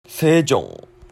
Sejong or Sejong City (Korean세종; Korean: [seːdʑoŋ]